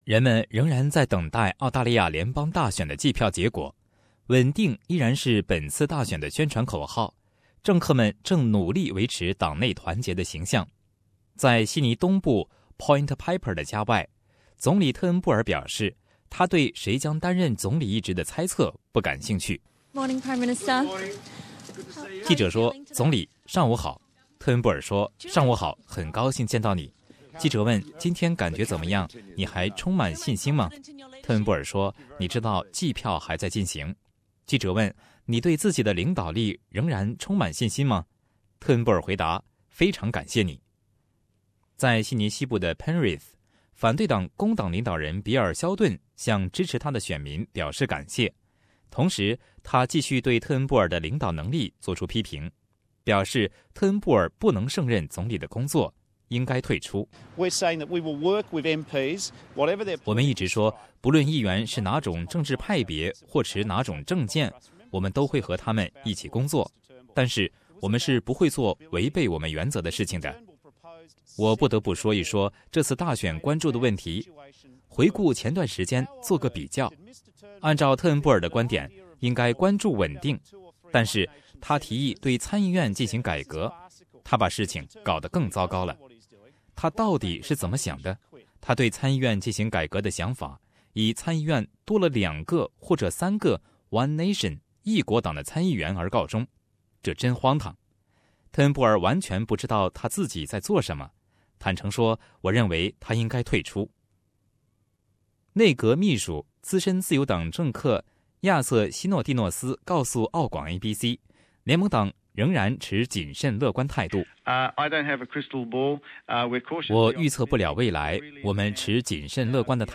現在計票還在進行，自由黨領導人特恩布爾和工黨領導人比爾*肖頓的前途受到人們關注。作為可以打破僵局的獨立議員也正受到極大的關注。請點擊收聽詳細的報道。